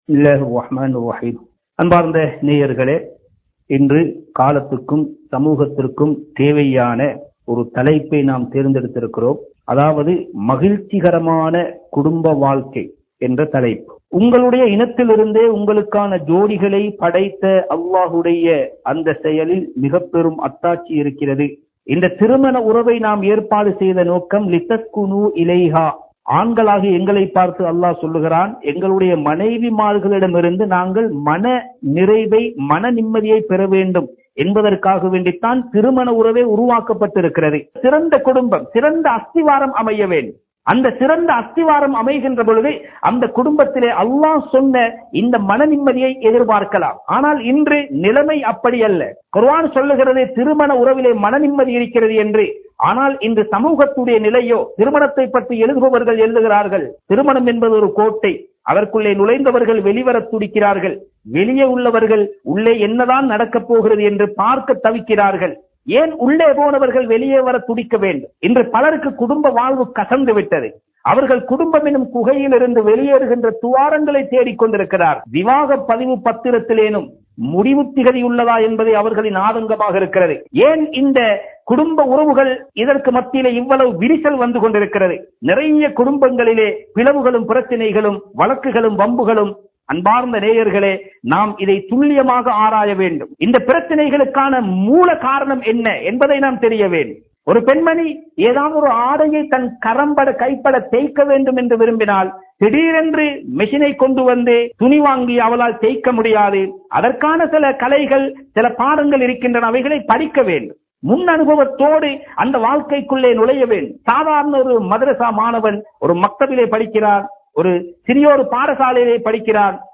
Bayans